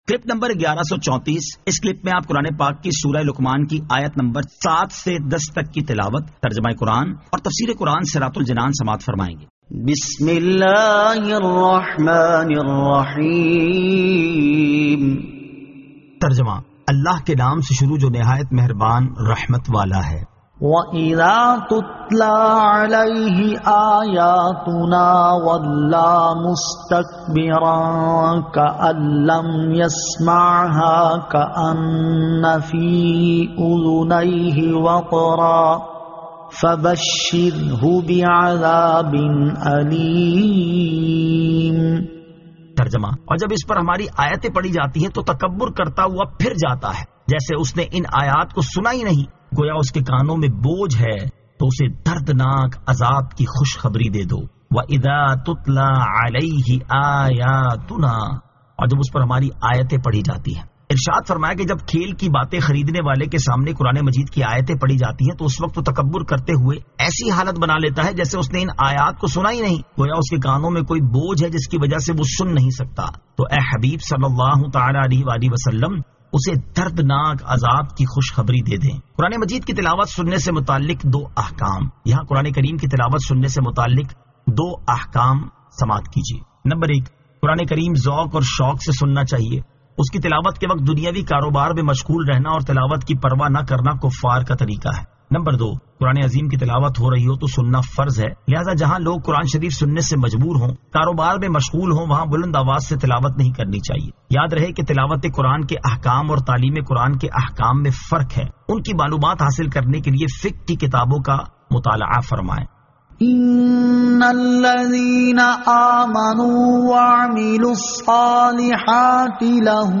Surah Luqman 07 To 10 Tilawat , Tarjama , Tafseer